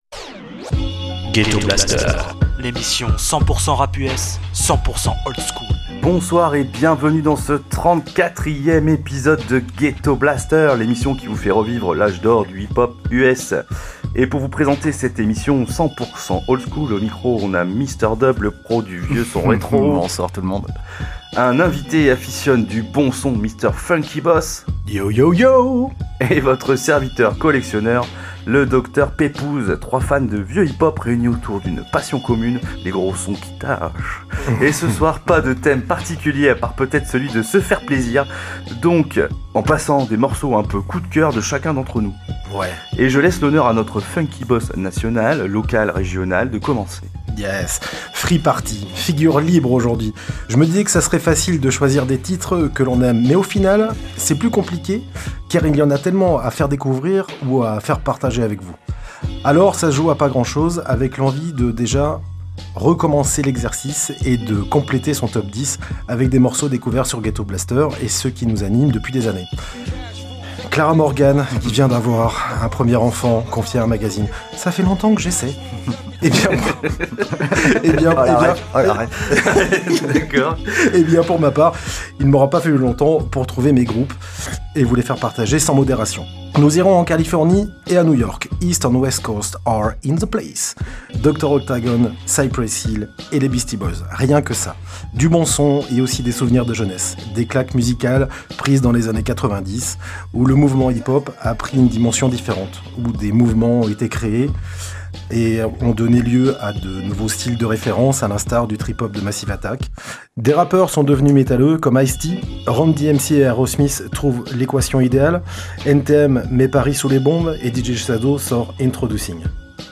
Le hip-hop US des années 80-90